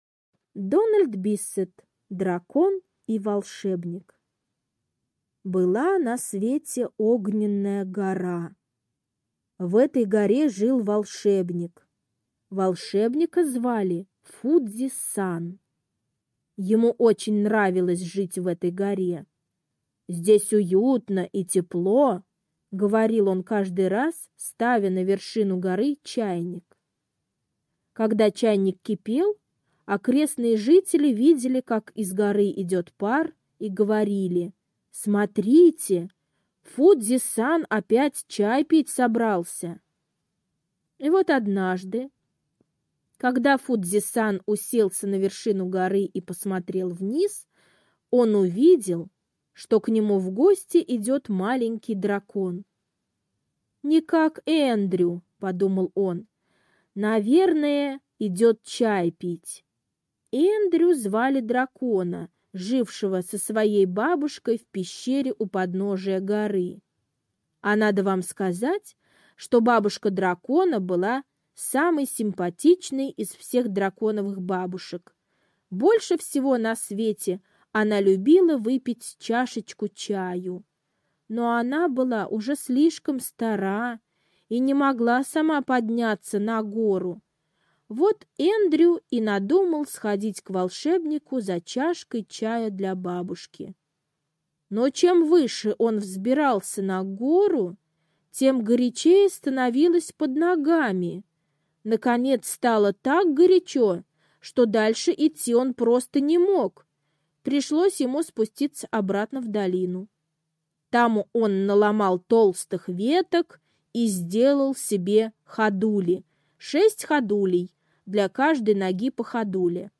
Дракон и Волшебник - аудиосказка Биссета Д. Сказка рассказывает про то, как драконы научились извергать огонь.